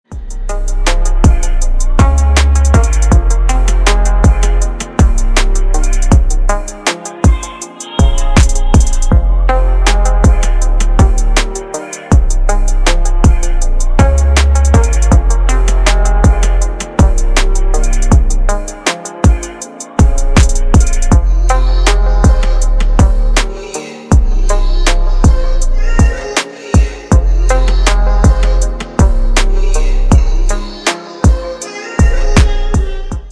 Спокойные басы
спокойные